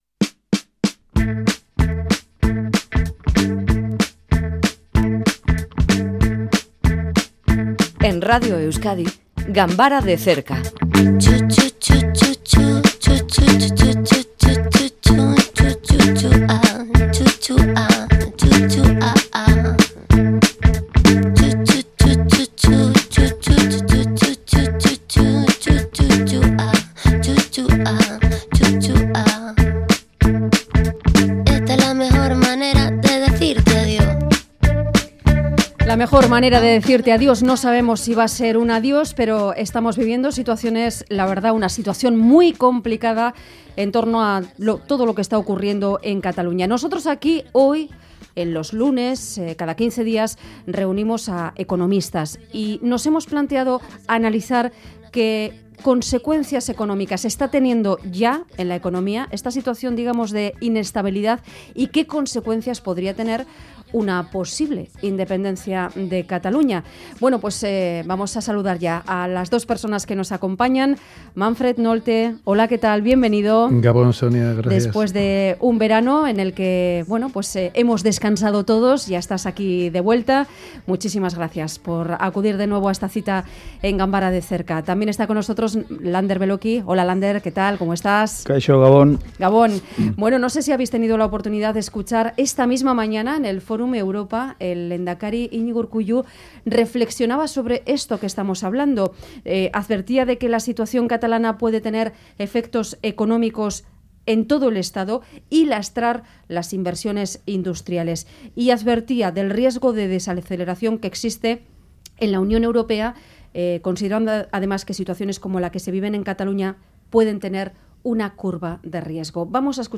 tertulia económica